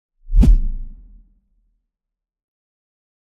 kick_long_whoosh_19.wav